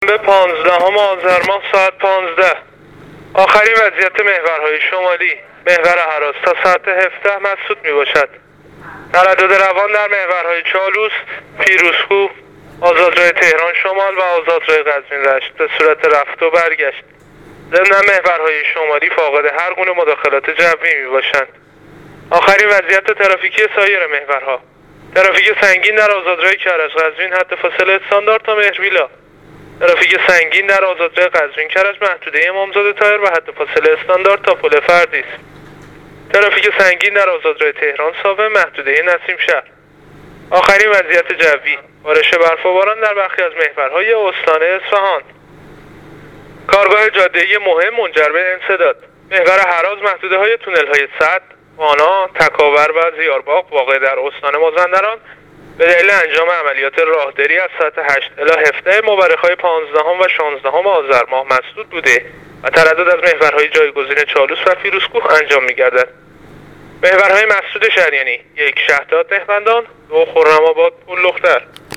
گزارش رادیو اینترنتی از آخرین وضعیت ترافیکی جاده‌ها تا ساعت ۱۵ پانزدهم آذر؛